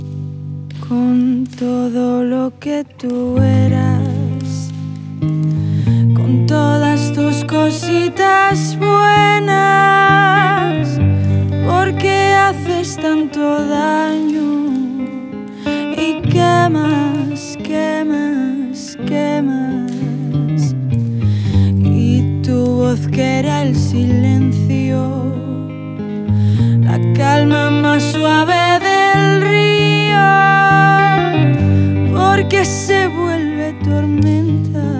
# Traditional Pop